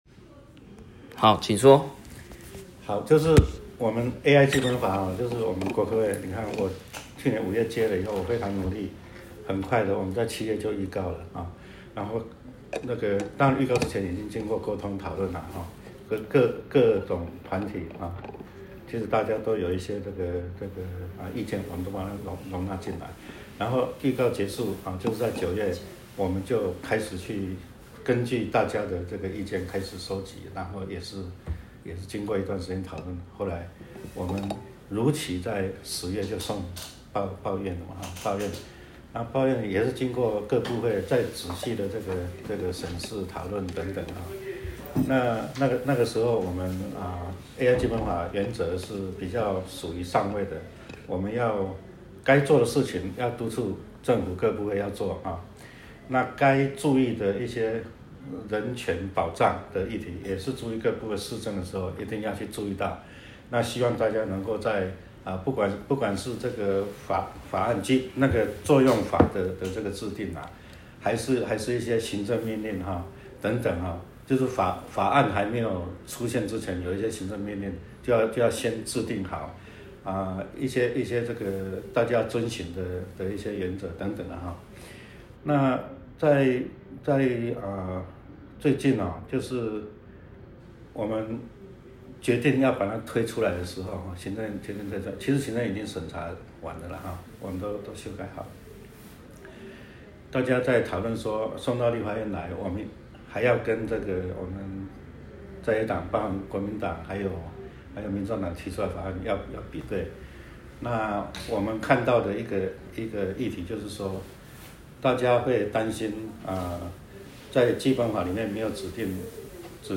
國科會主委拜會
時間：2025-03-07 10:30 與會人士：葛如鈞委員、國科會吳誠文主委